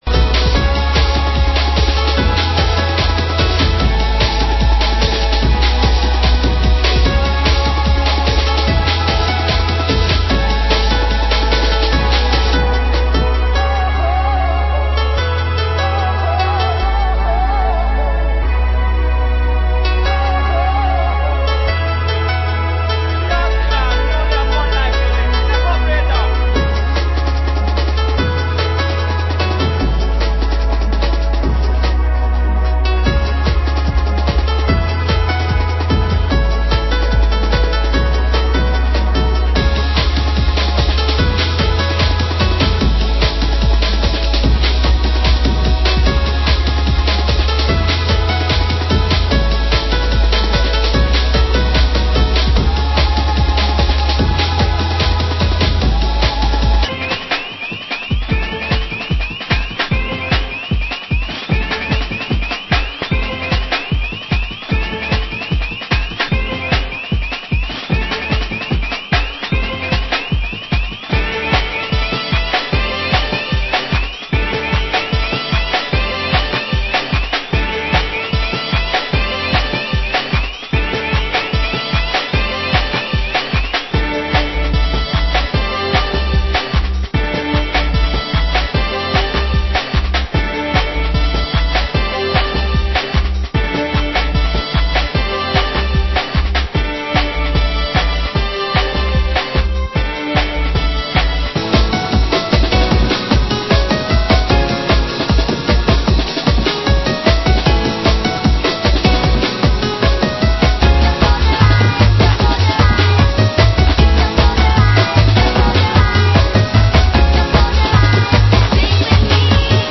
Genre: Hardcore